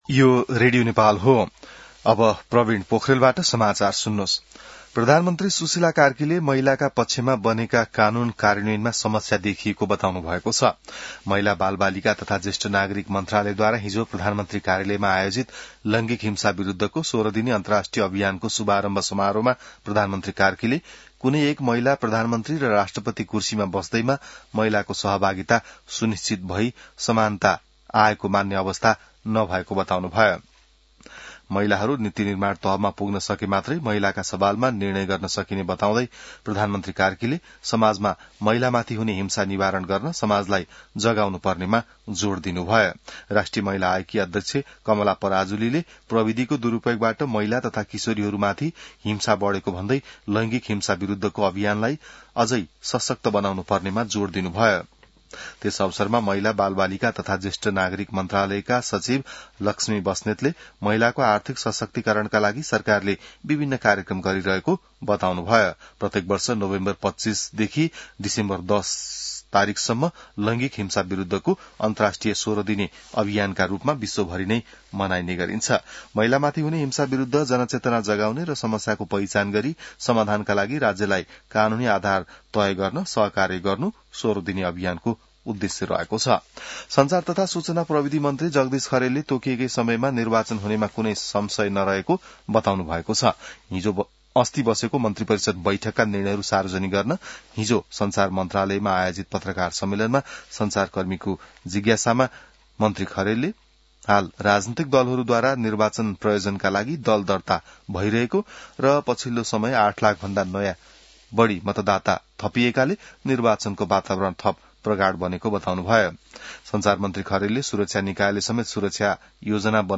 An online outlet of Nepal's national radio broadcaster
बिहान ६ बजेको नेपाली समाचार : १० मंसिर , २०८२